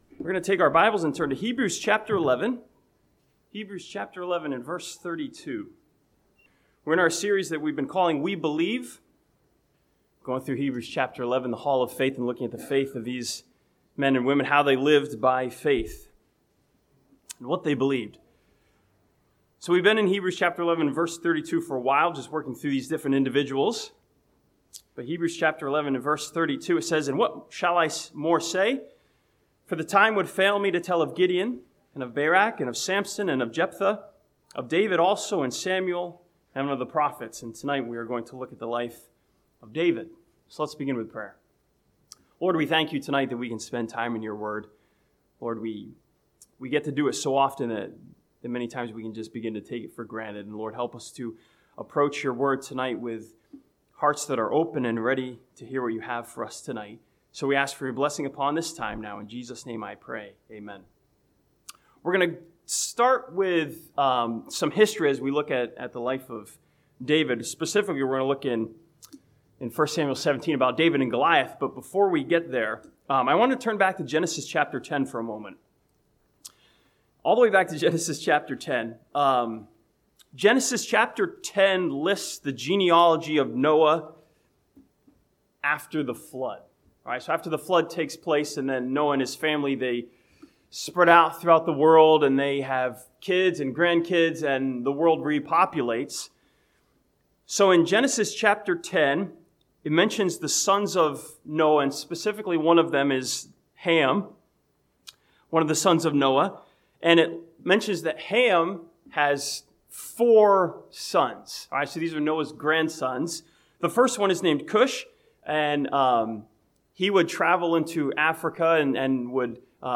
This sermon from Hebrews chapter 11 and 1 Samuel 17 examines the story of David's faith as he fought Goliath.